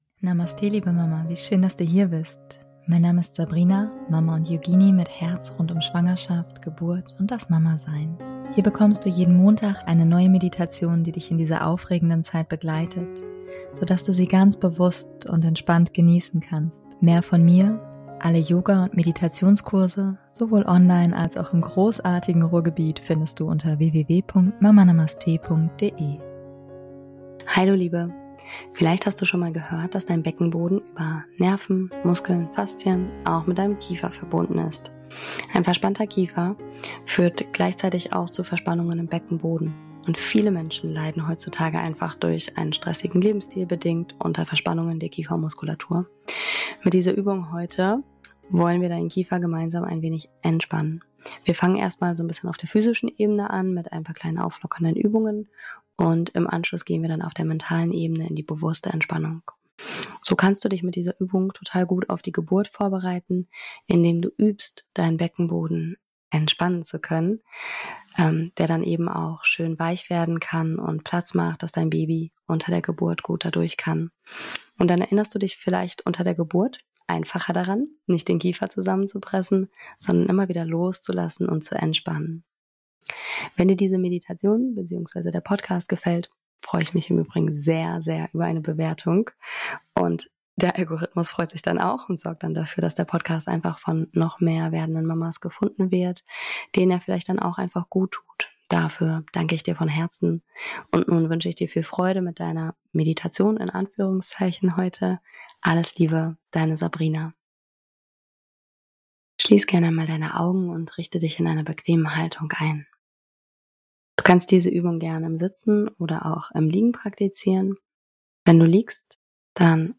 #185 - Kiefer entspannen - Übung und Meditation [Schwangerschaft]